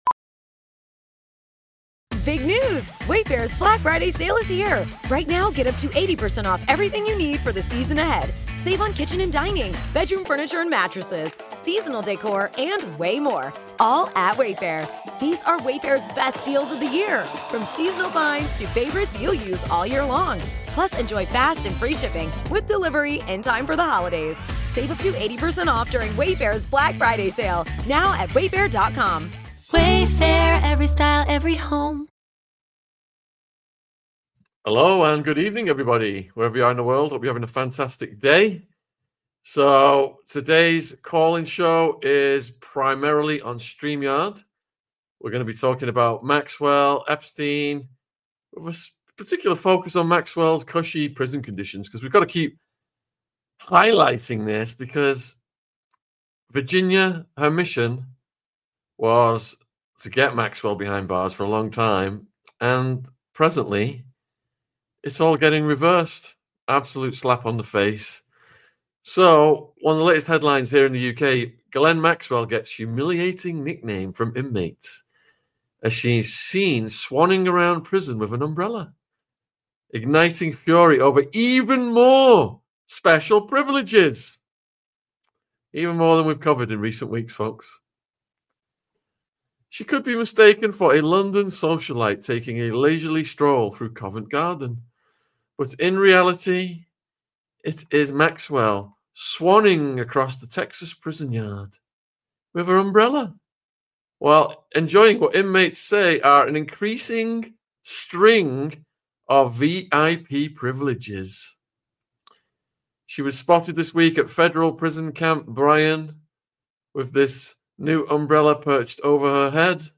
Epstein Call in Show - Is Maxwell Mollycoddled In Texas Prison?